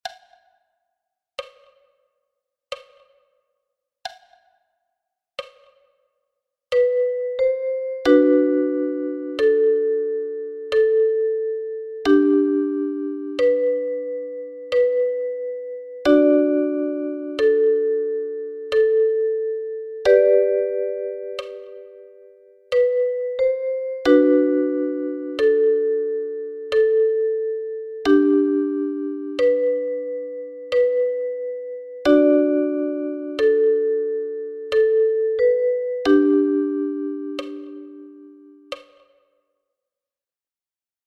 Play Ukulele – 41 Bearbeitungen deutscher Volkslieder (mit Online Sounds)